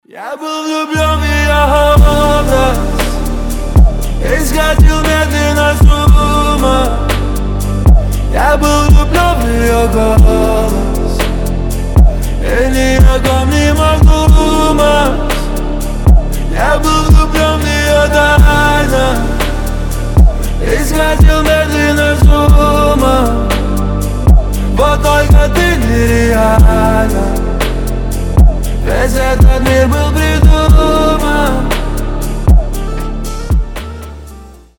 • Качество: 320, Stereo
мужской голос
лирика
медленные